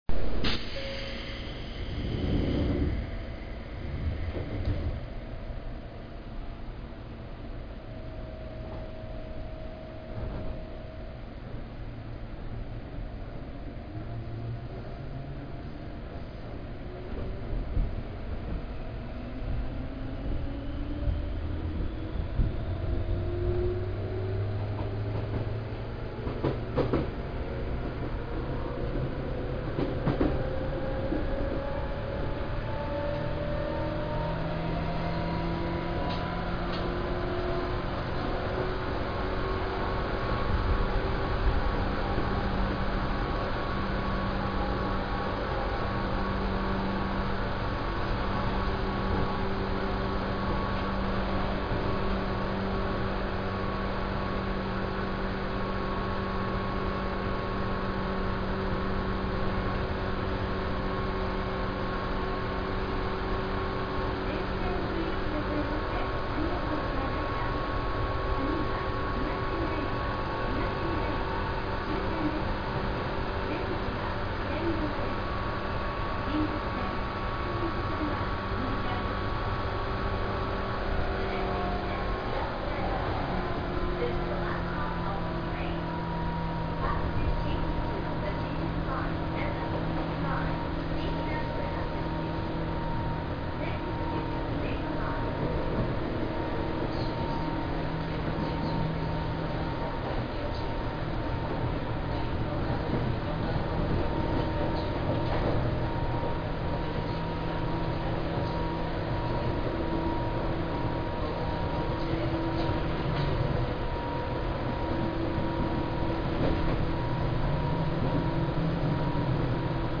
・新101系走行音
【西武園線】西武園→東村山（2分56秒）
ＪＲで言ったところの103系によく似た音…というより同じ音です。
101_Seibuen-HigashiMurayama.mp3